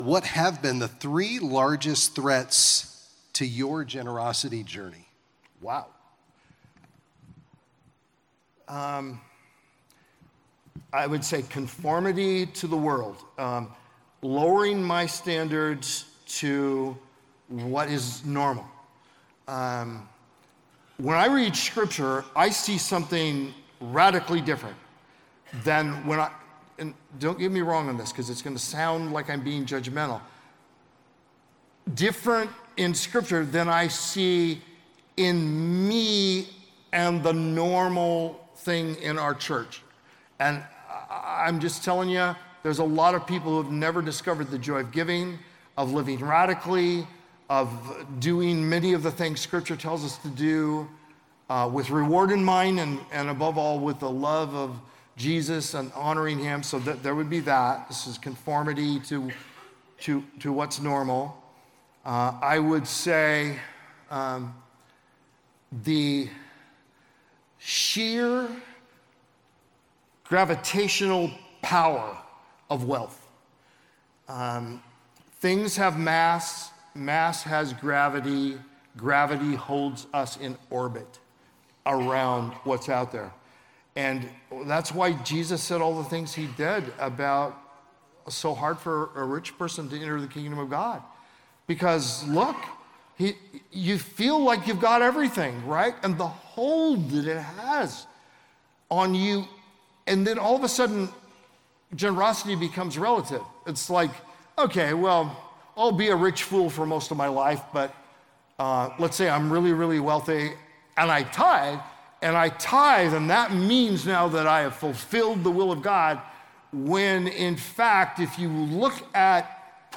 In this clip from a Q&A at the Kingdom Advisors Conference